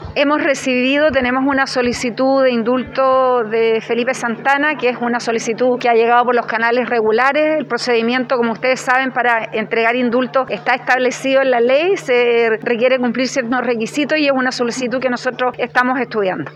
Así lo comentó a Radio Bío Bío Puerto Montt, la ministra de Justicia y Derechos Humanos, Marcela Ríos.